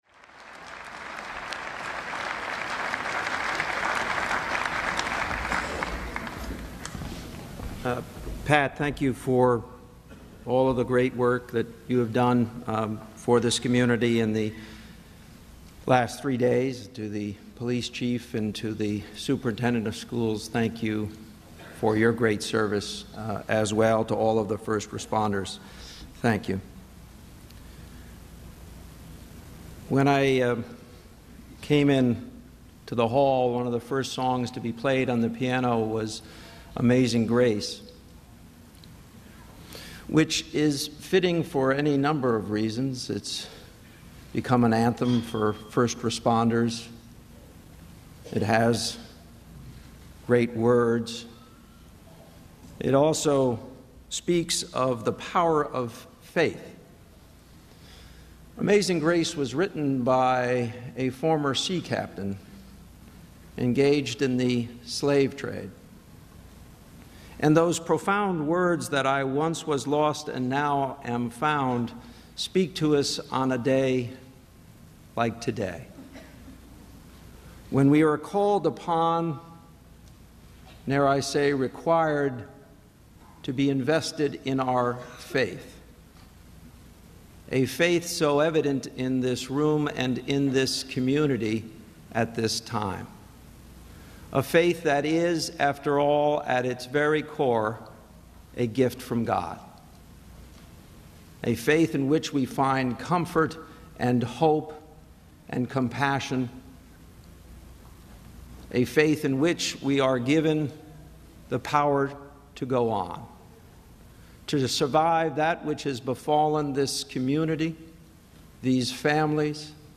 U.S. President Barack Obama speaks at an interfaith vigil for families of the victims of the shooting at Sandy Hook Elementary School in Newtown, Connecticut